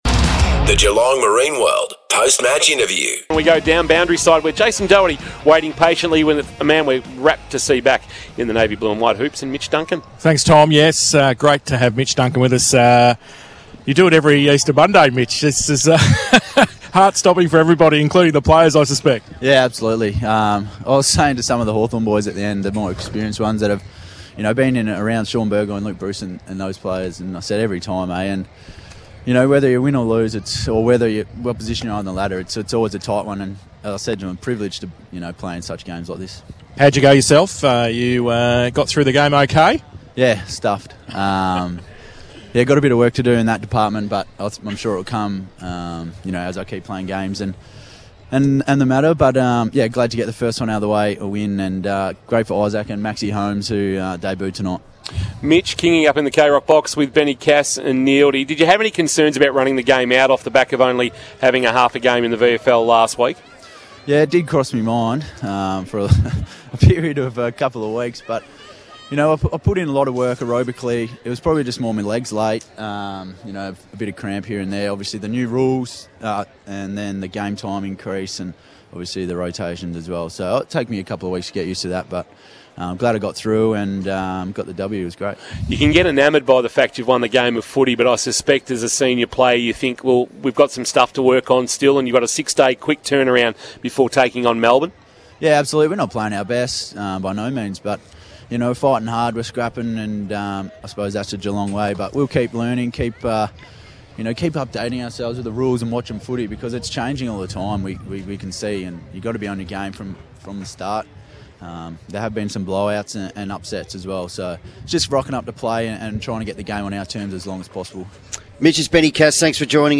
POST-MATCH INTERVIEW: MITCH DUNCAN - Geelong Cats